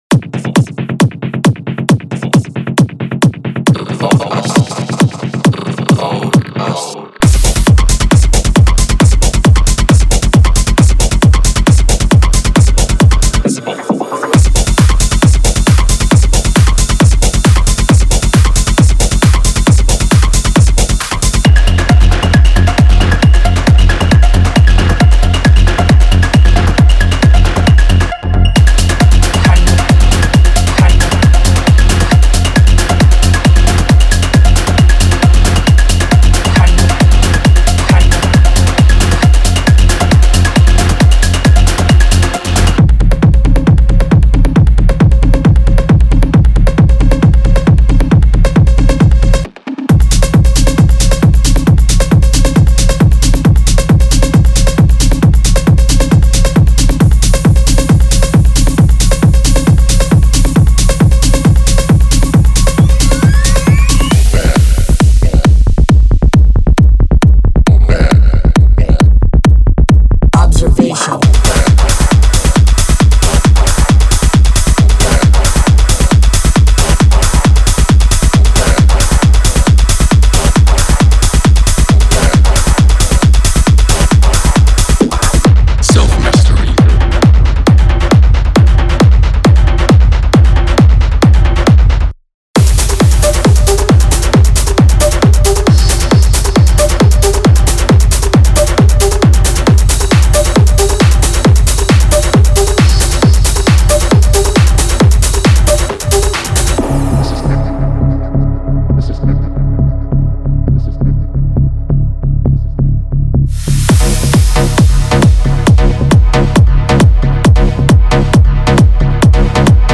Genre:Techno
すべてのサウンドは135 BPMでロックされており、最大限の柔軟性を持って制作されています。
ご注意：デモトラックは、このサンプルパックに収録されている音源のみで制作されており、パックの可能性の一部に過ぎません。